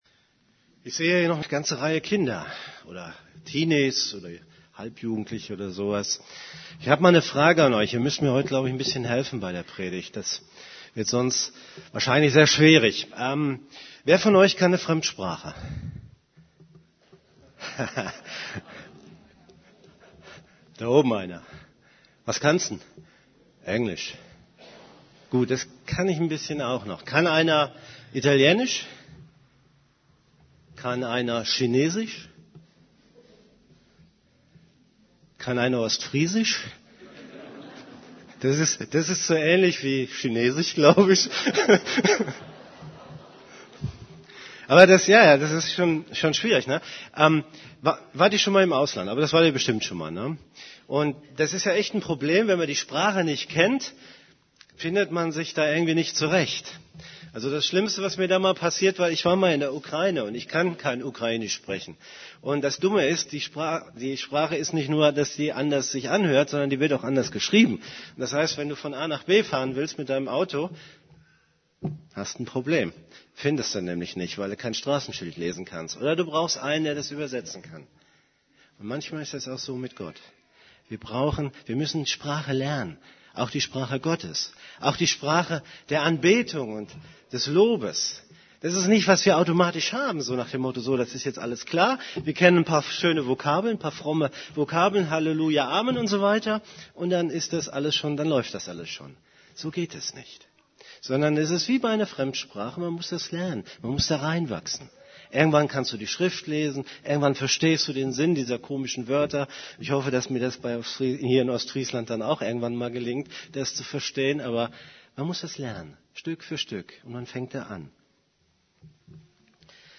> Übersicht Predigten Die Sprache des Reiches Gottes Predigt vom 07.